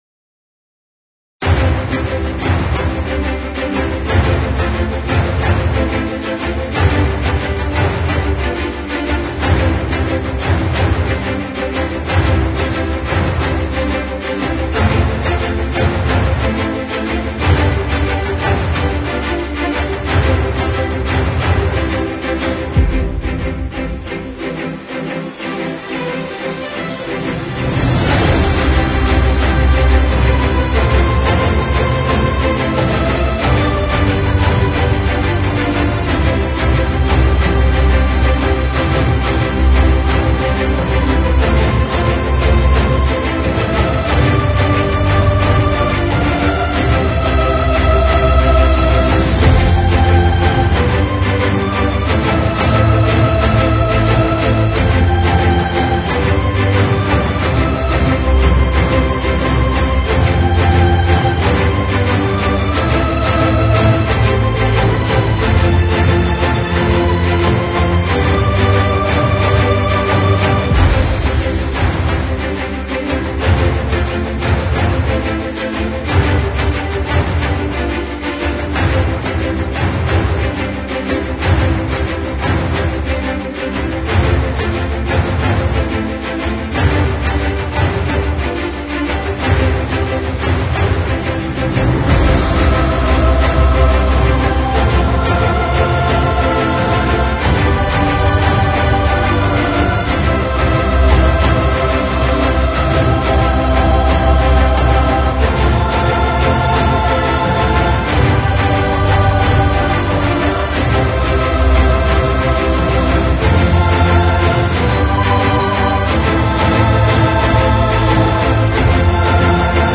描述：在颁奖典礼的轨道与成功和启发声！ 明亮的耳环，充满力量的定音鼓和美丽的主题！